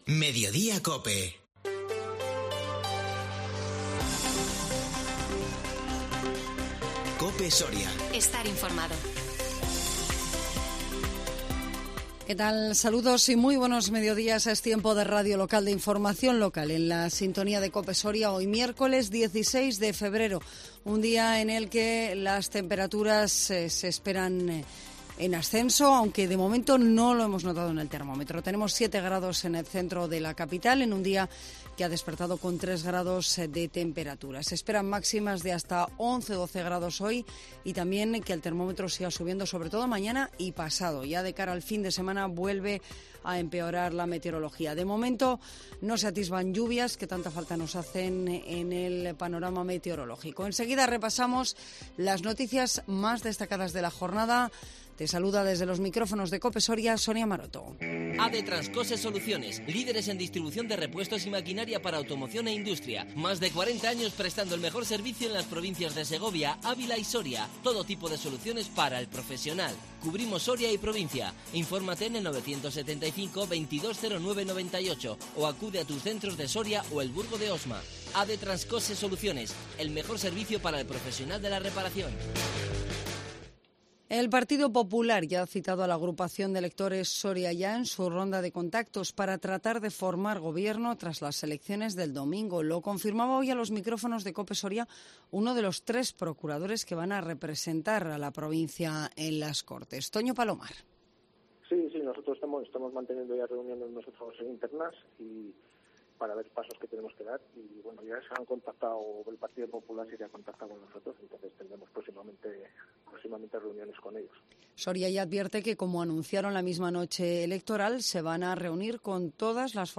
INFORMATIVO MEDIODÍA COPE SORIA 16 FEBRERO 2022